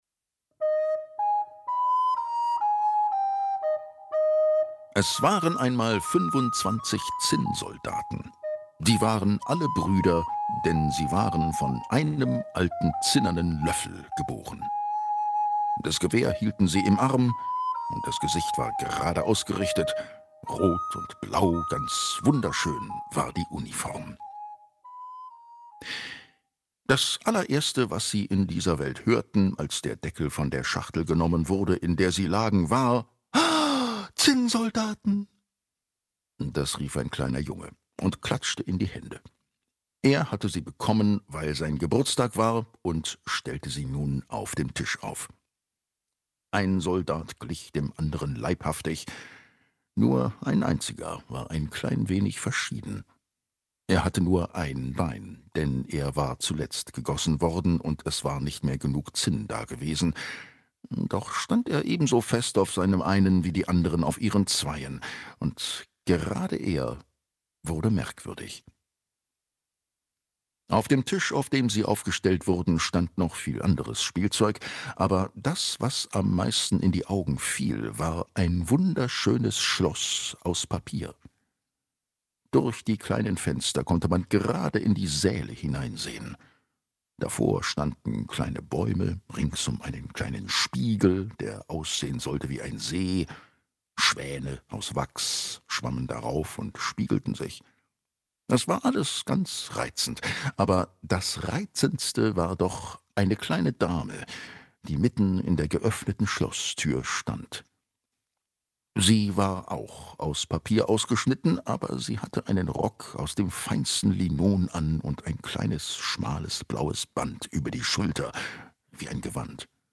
Musikalische Lesung
2025_Lesung_Der Zinnsoldat - Demo mit Musik.mp3